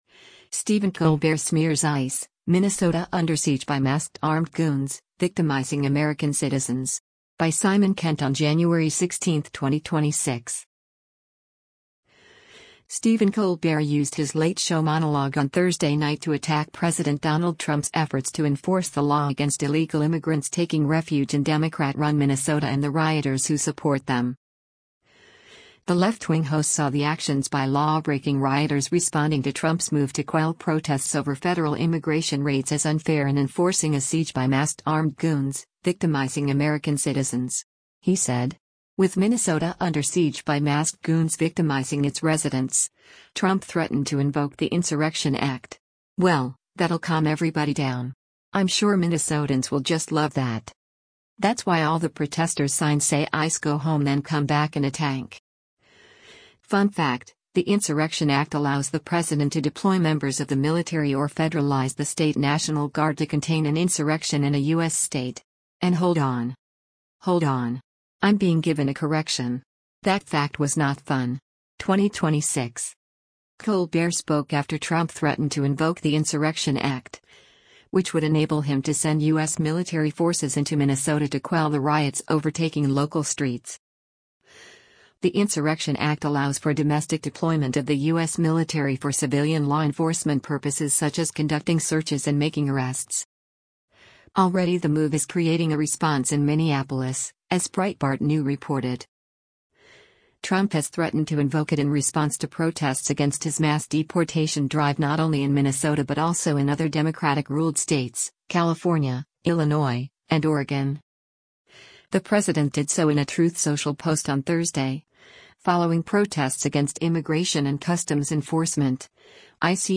Stephen Colbert used his Late Show monologue on Thursday night to attack President Donald Trump’s efforts to enforce the law against illegal immigrants taking refuge in Democrat-run Minnesota and the rioters who support them.